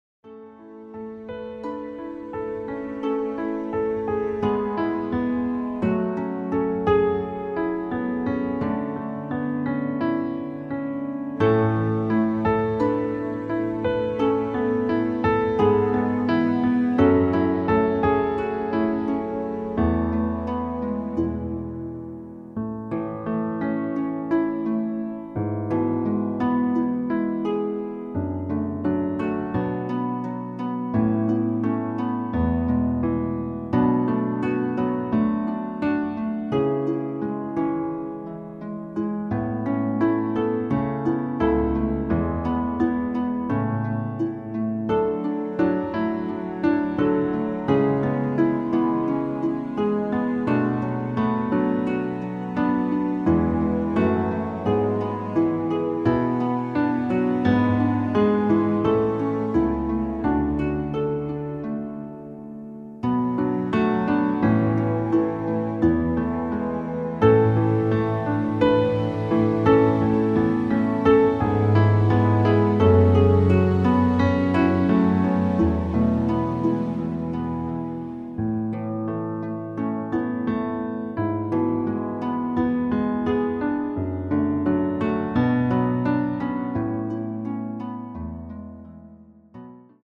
Klavier / Streicher